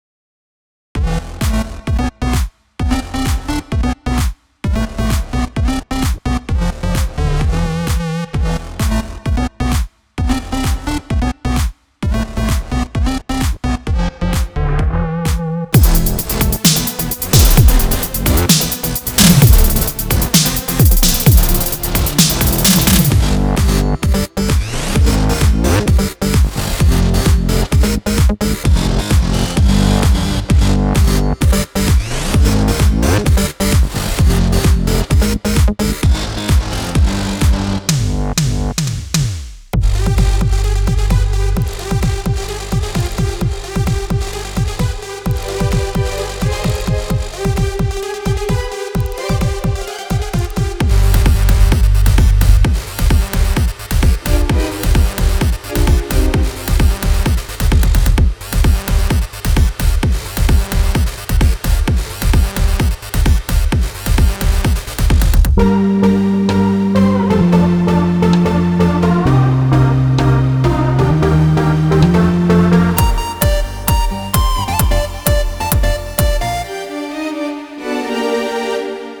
יפה מאוד ממש טוב אבל לדעתי חסר קצת בגרוב יעני אי אפשר להדליק את זה בריקודים אין כמעט קיק אני לא יודע מה בדיוק חסר טובים ממני יבינו מה אני מתכוון אבו הסיצטן והסולו מטורף